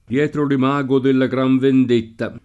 imago [im#go] o immago [imm#go] s. f. — latinismo poet. per «immagine»: come si convenne L’imago al cerchio [k1me SSi konv%nne l im#go al ©%rkLo] (Dante); Forse perché della fatal quïete Tu sei l’immago [f1rSe perk% ddella fat#l kUi-$te tu SS$i l imm#go] (Foscolo); Dietro l’imago de la gran vendetta [